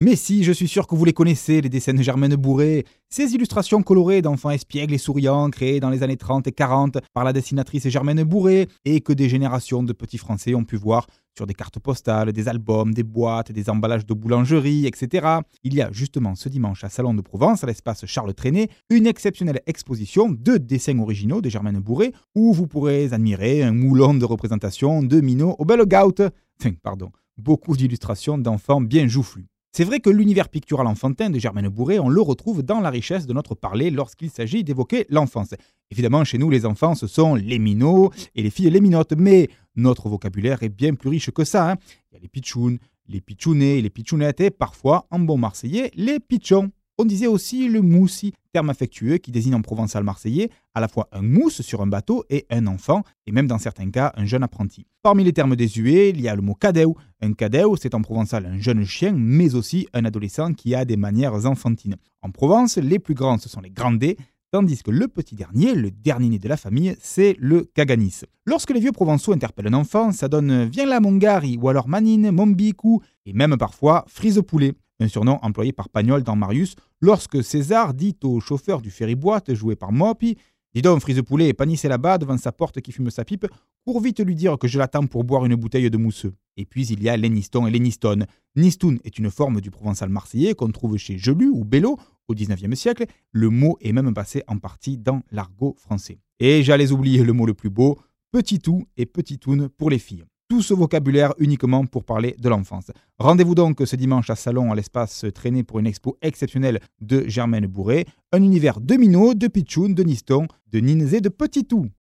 Ecouter l'annonce de l'expo "à la marseillaise" sur France Bleu Provence :